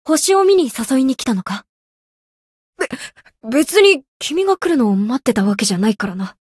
灵魂潮汐-南宫凛-七夕（摸头语音）.ogg